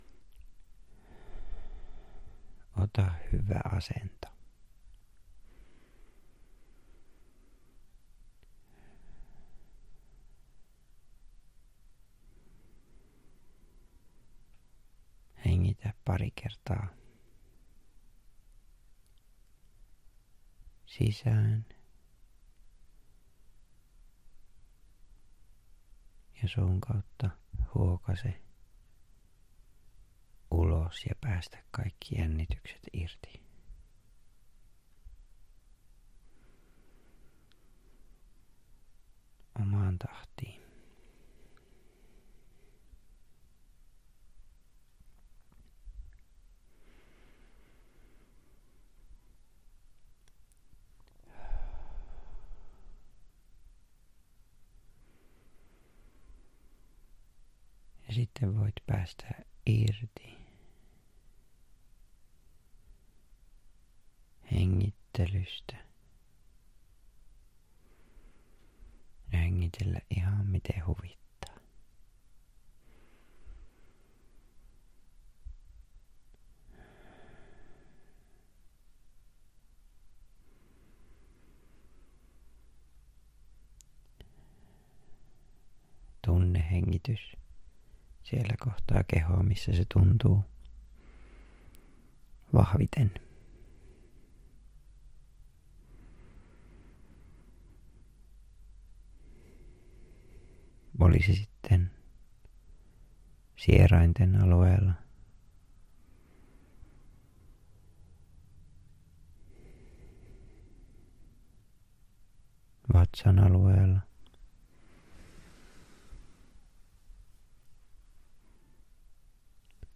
Kunnon kokovartalorentoutus-äänite; (ASMR; hengityksen kanssasäätely; 44 minuuttia)
ASMR tarkoittaa rentouttavia, mielihyvää tuottavia aistimuksia, jotka voivat syntyä tietynlaisista äänistä, hengityksen rytmistä ja puhujan läheisyyden mielikuvasta. Äänitteessä hyödynnetään näitä rentouttavia elementtejä kehon ja mielen syvärentoutuksen tukena.
preview-Rento-kehon-aistiminen-ASMR-hengityksen-kanssasaately.mp3